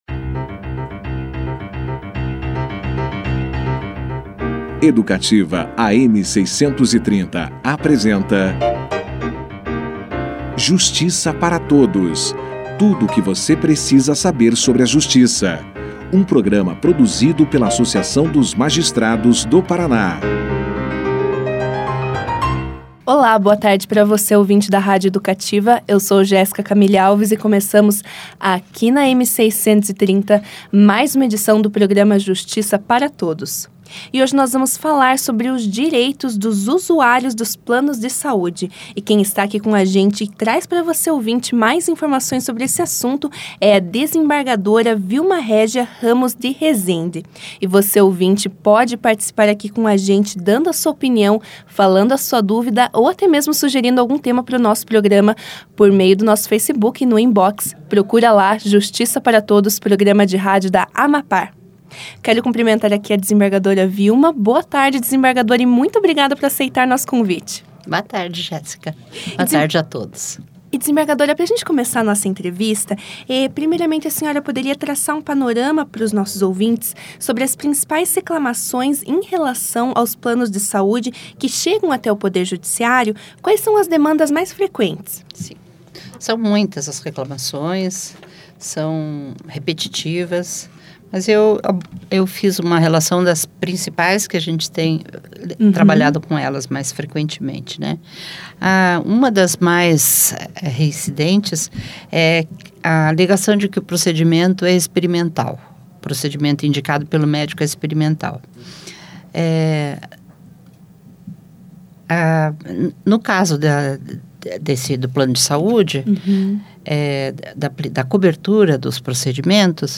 No programa da AMAPAR desta segunda-feira (19), a desembargadora Vilma Régia Ramos de Rezende esclareceu dúvidas e trouxe mais informações para os ouvintes da rádio Educativa, AM 630, sobre os planos de saúde.
Confira na íntegra a entrevista com a desembargadora Vilma Régia Ramos de Rezende